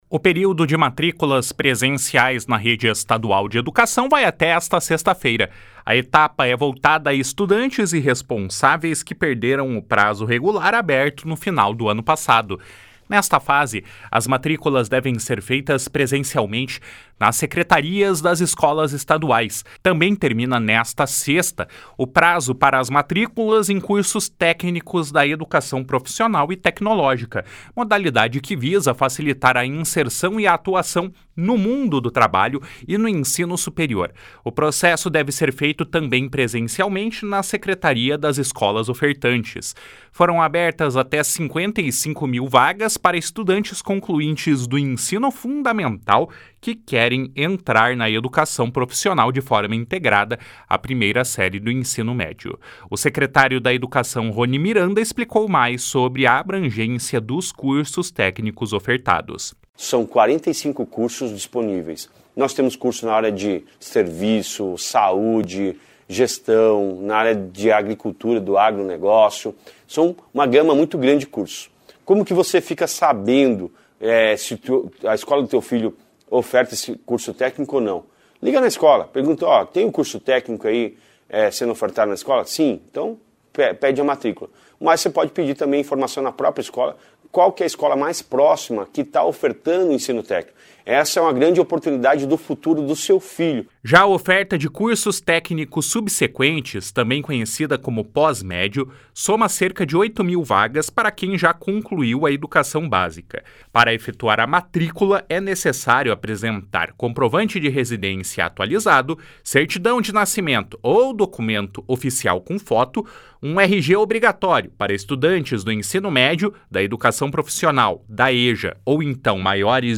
O secretário Estadual da Educação, Roni Miranda, explicou mais sobre a abrangência dos cursos técnicos ofertados. // SONORA RONI MIRANDA //